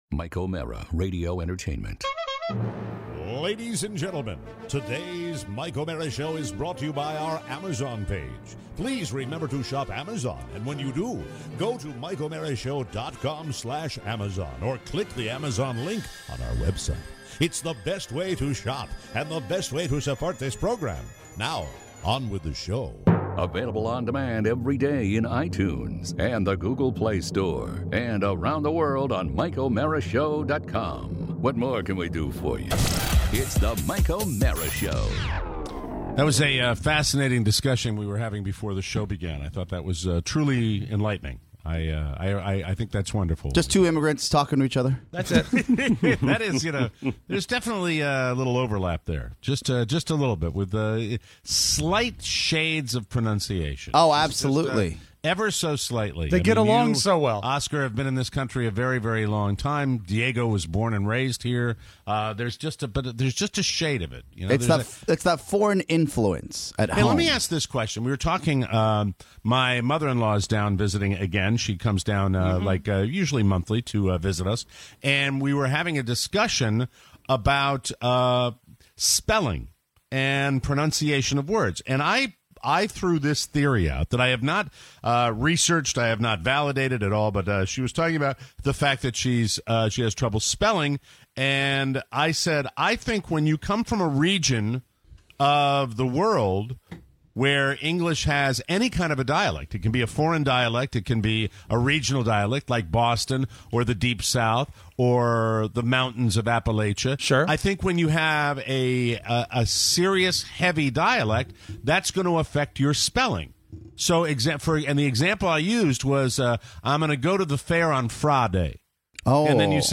Your calls!